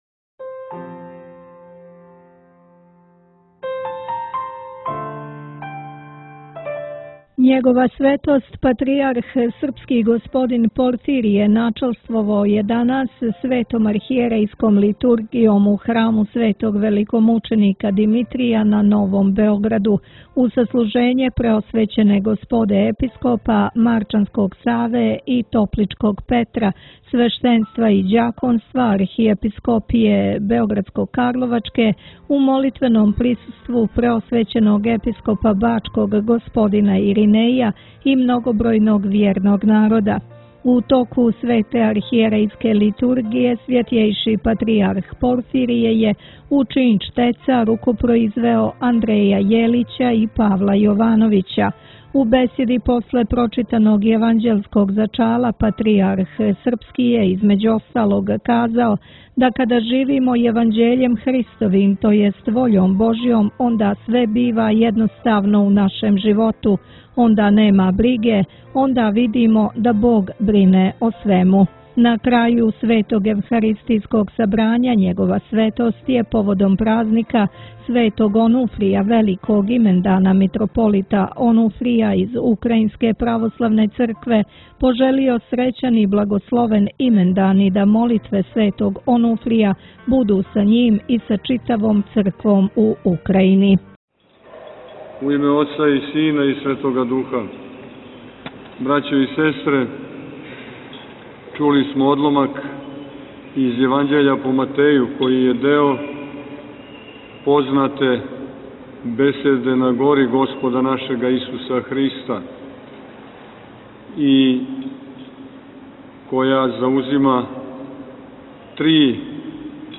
Бесједа
у храму Светог Архангела Михаила